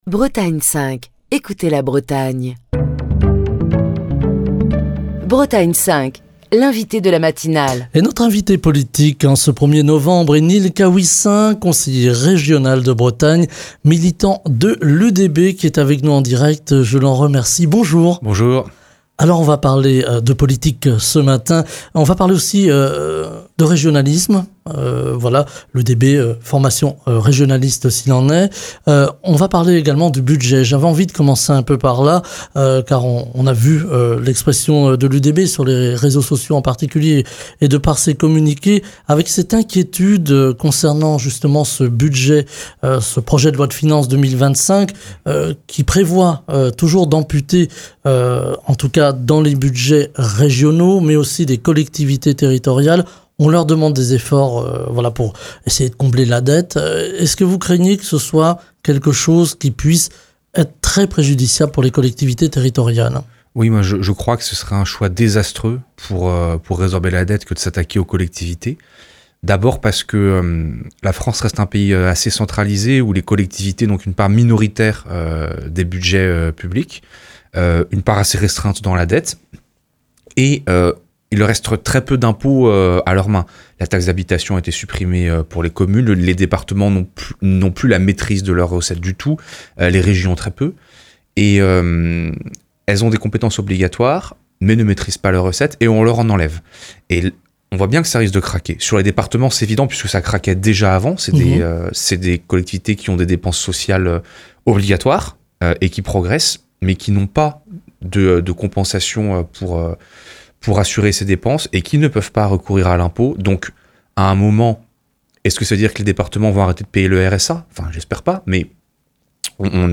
Ce vendredi, Nil Caouissin, conseiller régional de Bretagne, militant de l'UDB - Union Démocratique Bretonne, est l'invité politique de la matinale de Bretagne 5. Nil Caouissin commente l'actualité politique et en premier lieu le projet de loi de finances 2025 et ses conséquences sur le budget des collectivités territoriales. Il plaide notamment pour une plus grande autonomie des régions, ce qui leur permettrait d'être plus efficaces dans la gestion de leurs finances et de coller au plus près aux besoins des territoires et aux attentes des habitants.